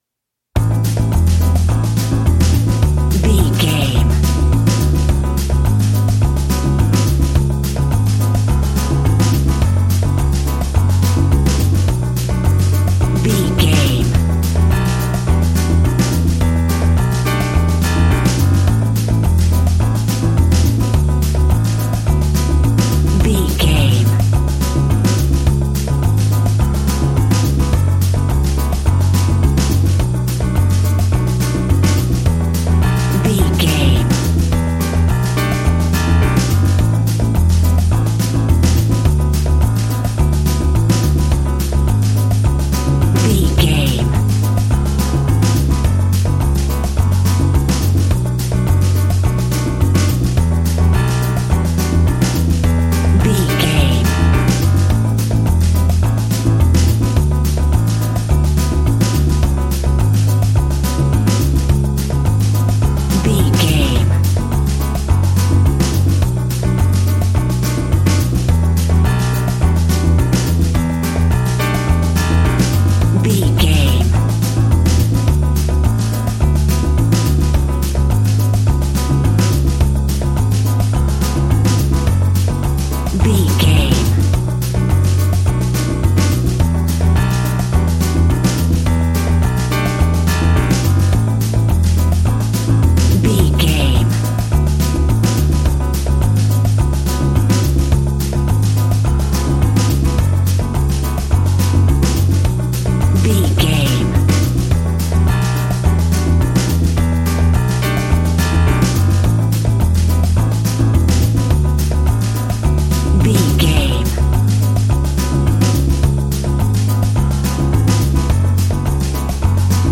Aeolian/Minor
flamenco
maracas
percussion spanish guitar